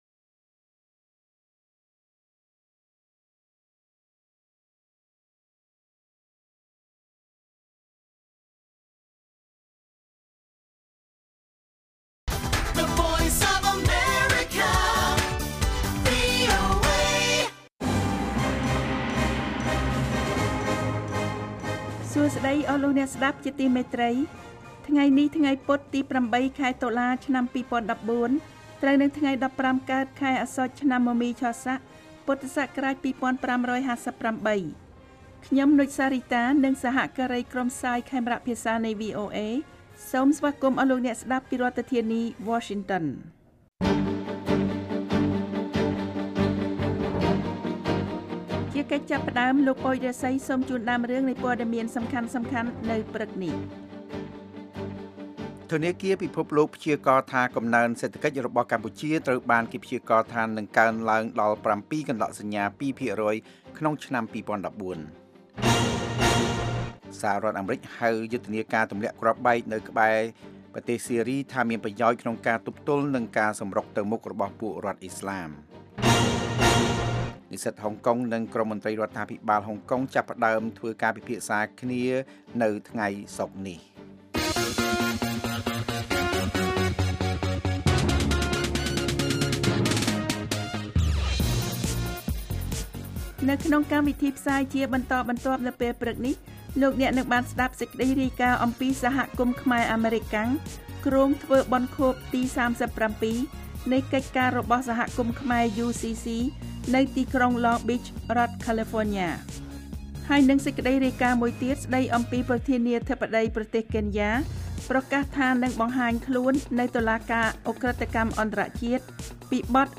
This daily 30-minute Khmer language radio program brings news about Cambodia and the world, as well as background reports, feature stories, and editorial, to Khmer listeners across Cambodia.